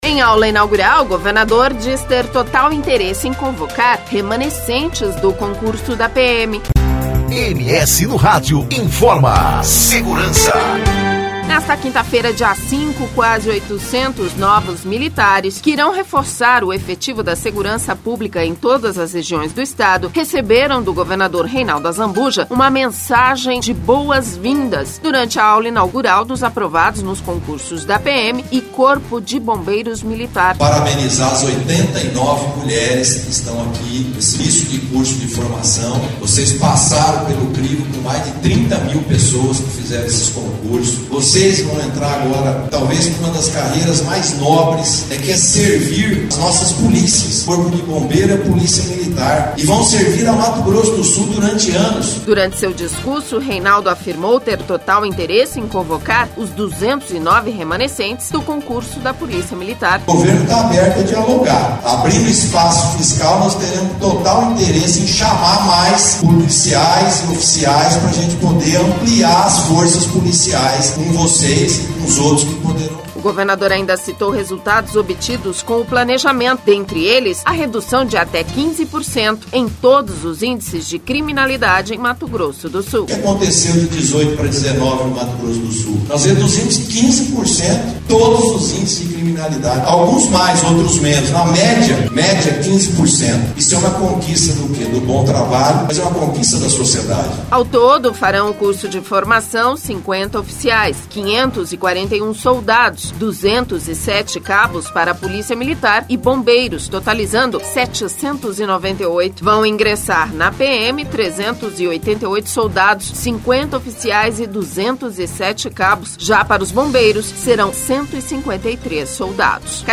Campo Grande (MS) – Nesta quinta-feira, dia 05, quase 800 novos militares, que irão reforçar o efetivo da segurança pública em todas as regiões do Estado, receberam do governador Reinaldo Azambuja uma mensagem de boas vindas, na aula inaugural dos aprovados nos concursos da PM e Corpo de Bombeiros Militar.
Durante seu discurso Reinaldo afirmou ter total interesse em convocar os 209 remanescentes do concurso da Polícia Militar (PM).